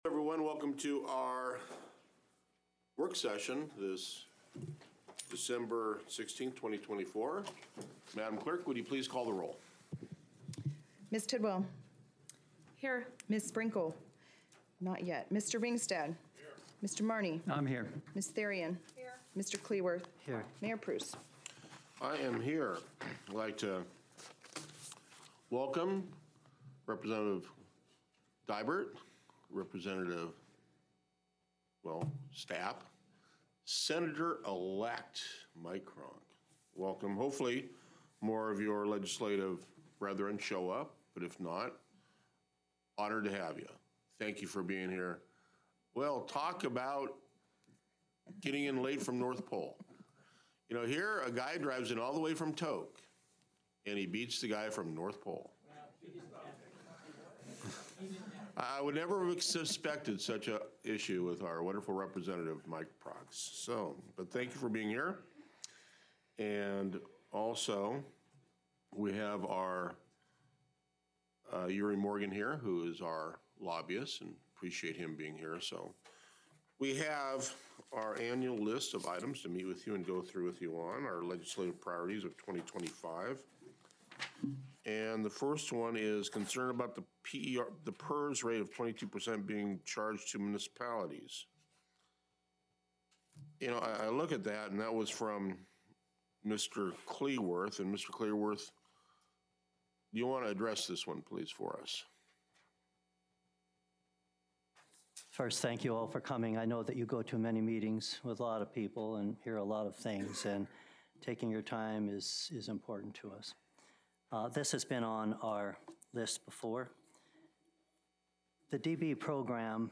Special Council Work Session with Interior Delegation: 2025 Legislative & Capital Priorities